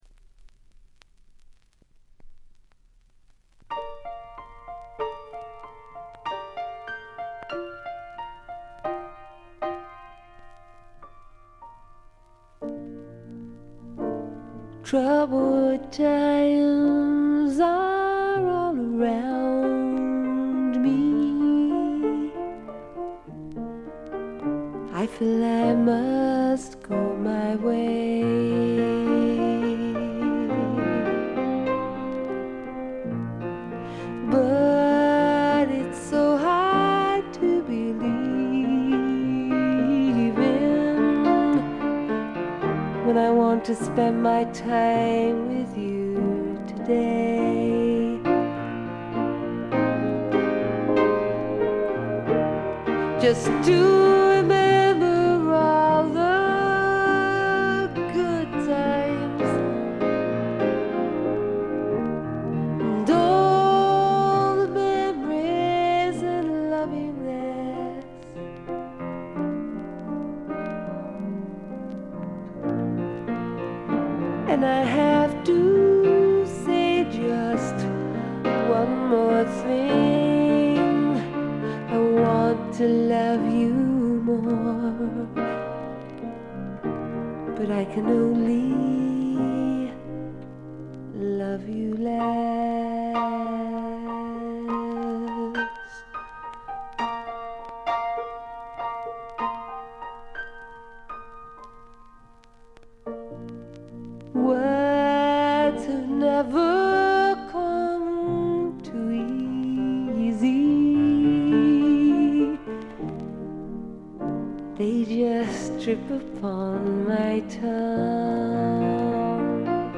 バックグラウンドノイズが常時出ており静音部ではやや目立ちます。
英国の女性シンガー・ソングライター／フォークシンガー。
試聴曲は現品からの取り込み音源です。